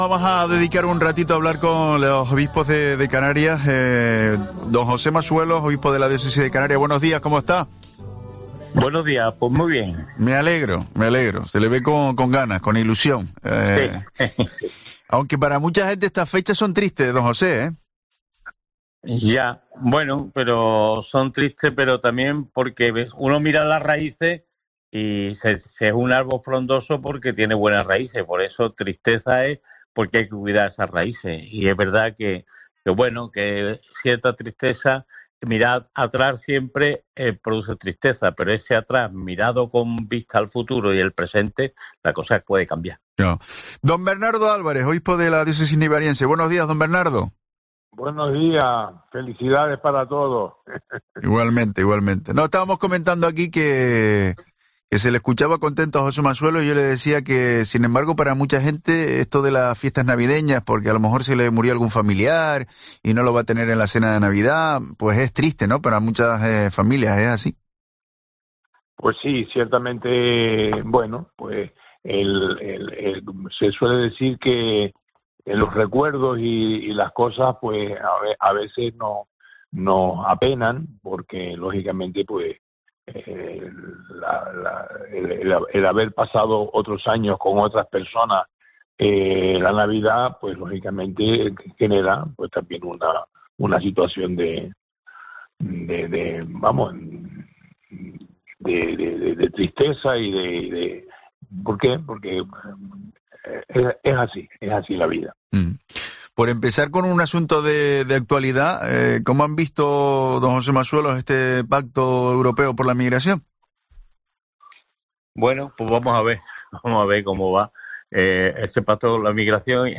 Entrevista a los dos obispos canarios en Navidad